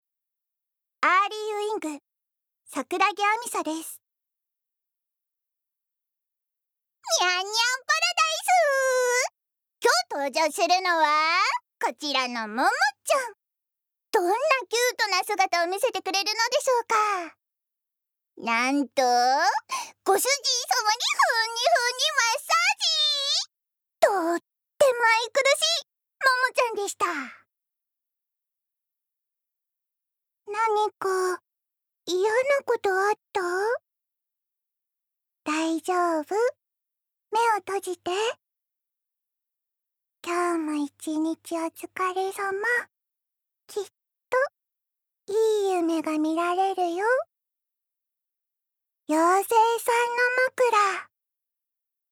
ボイスサンプル
ナレーションALL